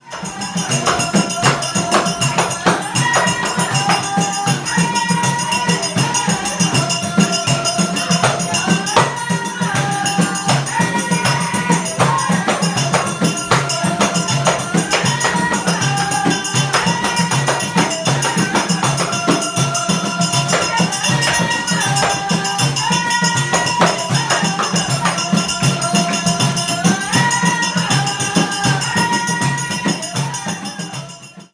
Berber band
As one of our 'Cultural Experiences' we invited a local Berber band to play for the group one evening. This turned out to be five (later joined by a sixth) woman ensemble who drummed & sang for us - a powerfully loud experience in a tile-lined room! The music was in hypnotic 7/8, 10/8 and 12/8 rhythms and the call & response singing demanded that we get up and dance to it - encouraged by the staff who joined in both the dancing and singing themselves.
The band members took turns to dance solo, starting off with recognisable Middle Eastern steps but then doing a sort of intermittent 'bunny hop' accompanied by loud bangs on a drum.
berberBand.mp3